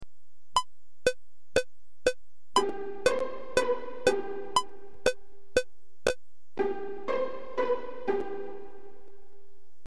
Note, these are all the same tempo; 120 BPM (beats per minute).